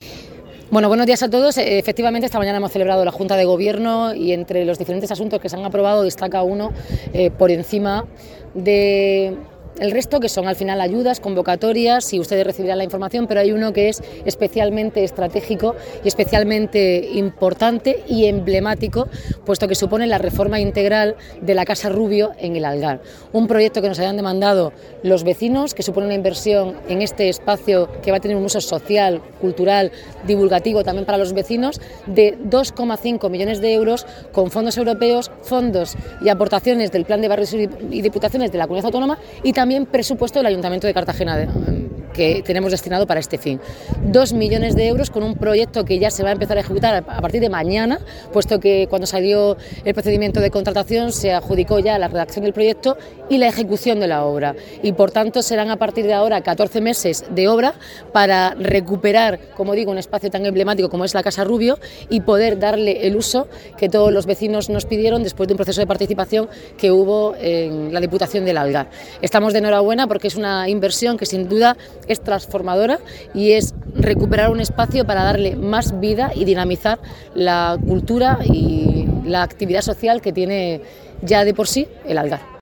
Enlace a Declaraciones de Noelia Arroyo sobre el proyecto de la Casa Rubio